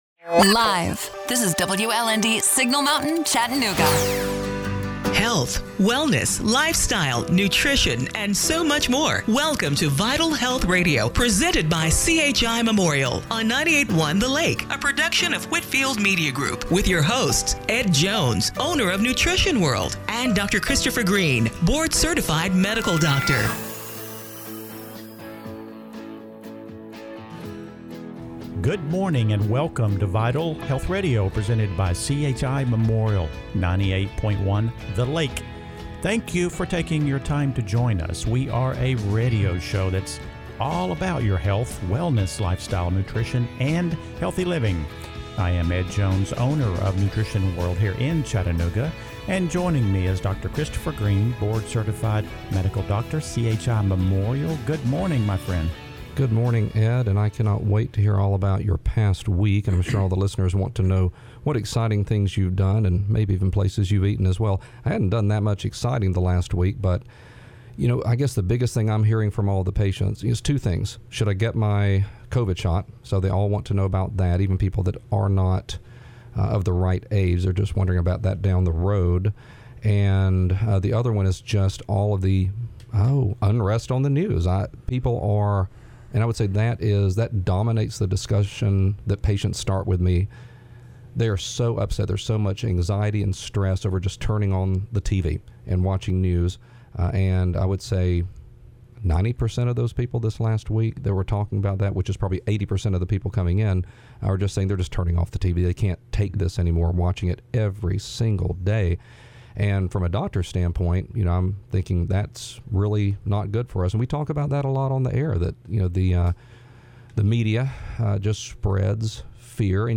February 7, 2021 – Radio Show - Vital Health Radio